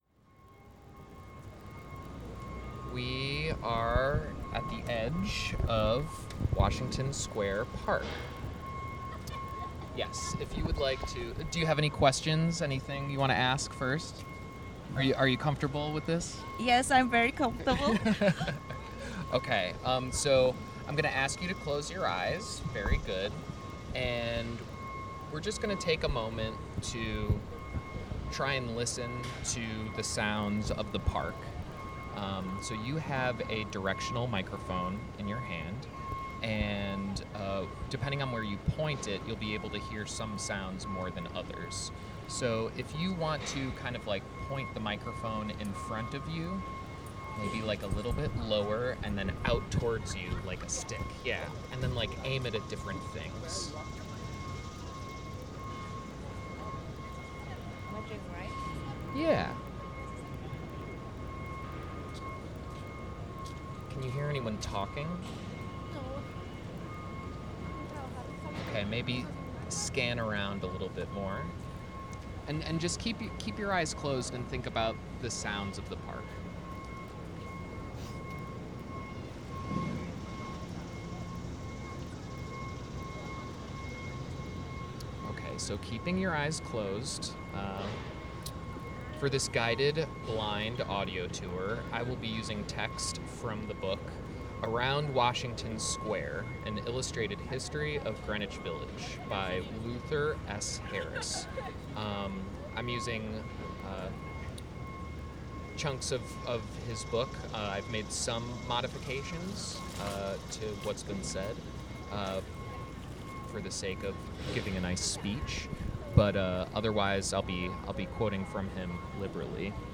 “Close Your Eyes” Using a headphone splitter, we were able to create a little guided audio tour using two headphones, two microphones (one for the speech, one for the listening to ambient noises), and the Zoom to record everything while also being the amplifier for the tour guide and tourist.
The tourist, closing their eyes and being lead by the tour guide, holds a shotgun mic in order to hear directionally around them.
You are invited to close your eyes and listen to a short historical tour of Washington Square Park. http
washingtonSq_audio_tour.mp3